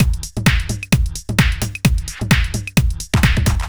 130 Driller Killer Toms.wav